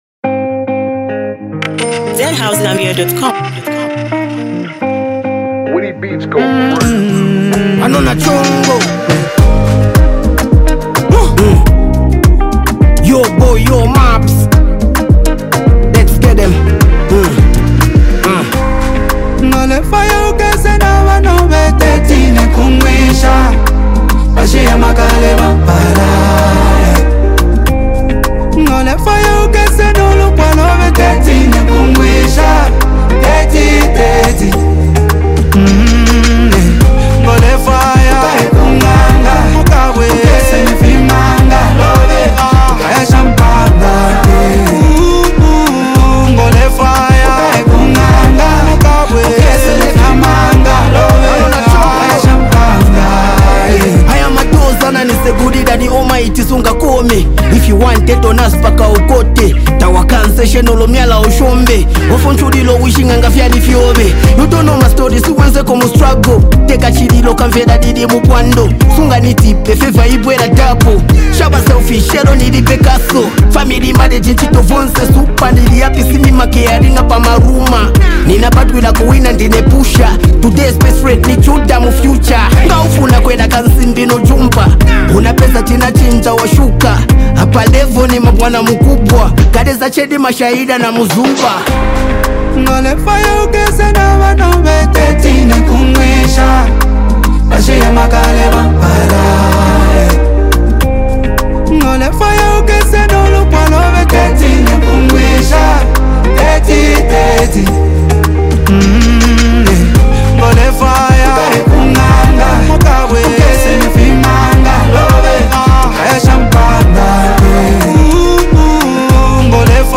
a powerful anthem blending rap and soulful vocals
delivers a heartfelt chorus